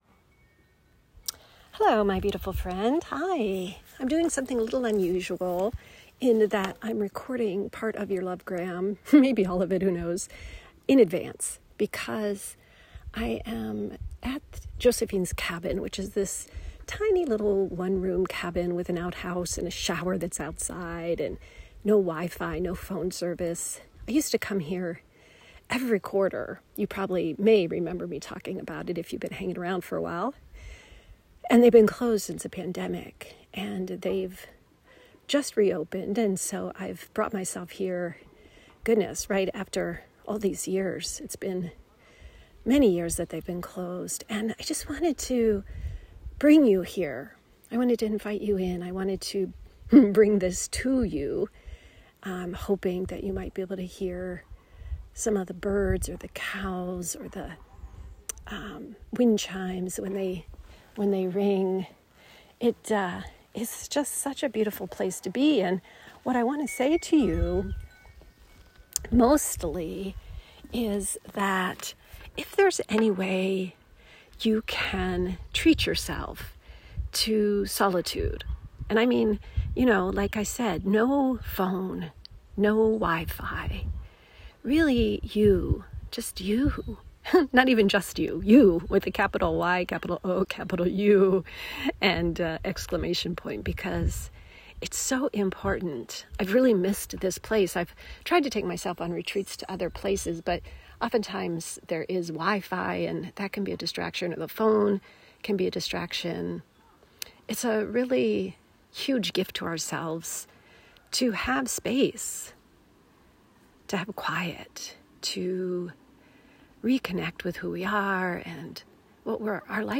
💥💓💥 Today’s audio LoveGram was recorded for you last week while I was on a personal retreat at this little cabin I love so much.